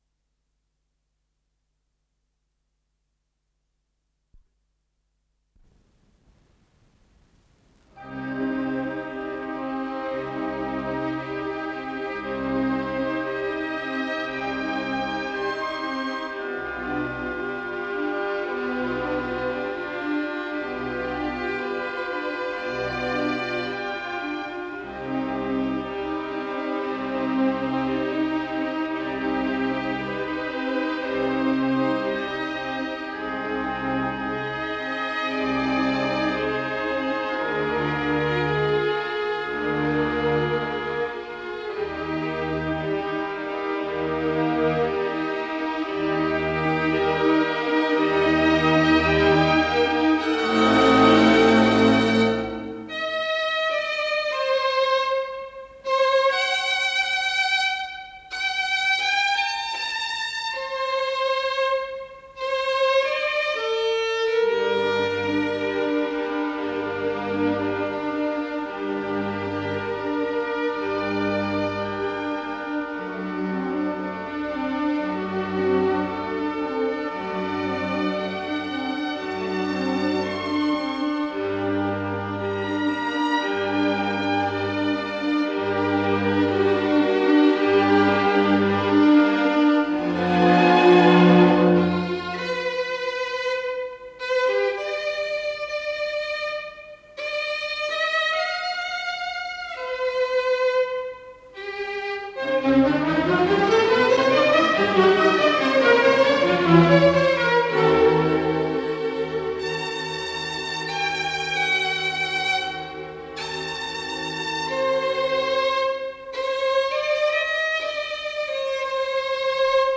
Исполнитель: Камерный оркестр Ленинградской Государственной филармонии
до мажор